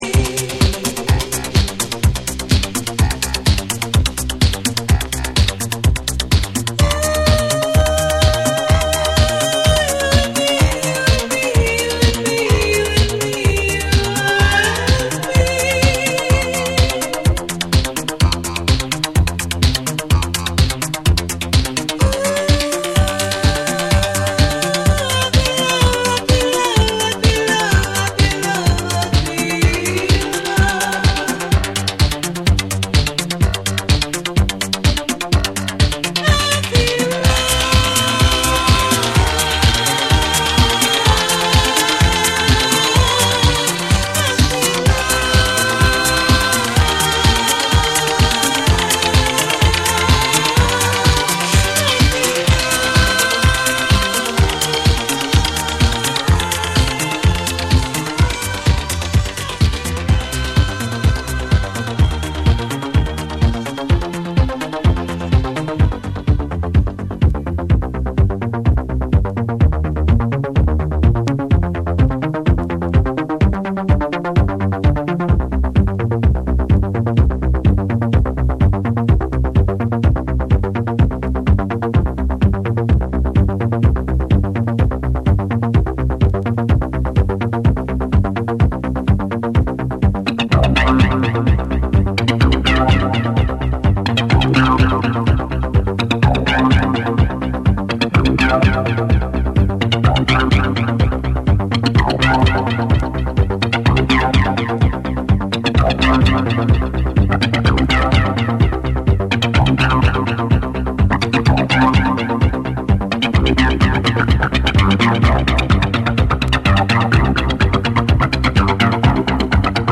クラブの余韻を感じさせる深みと、ホームリスニングに最適な親密さを兼ね備えた好内容です！
TECHNO & HOUSE / NEW WAVE & ROCK